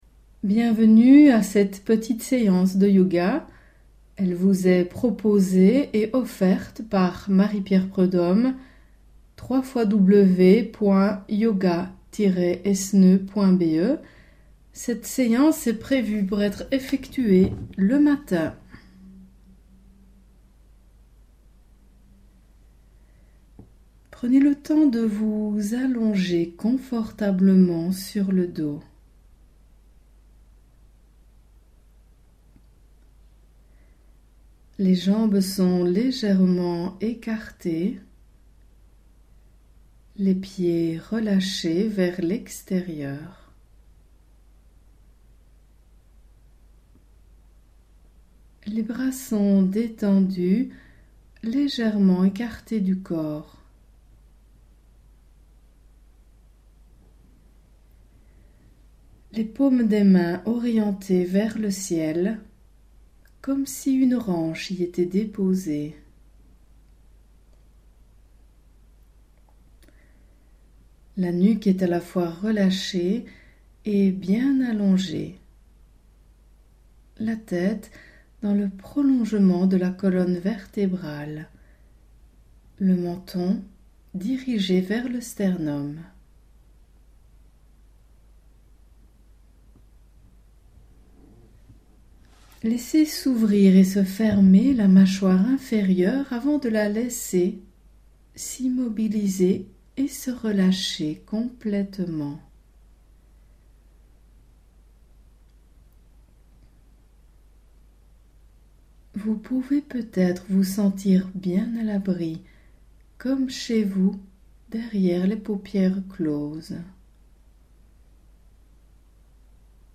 Un cours en mp3 pour une séance de Yoga relaxante en été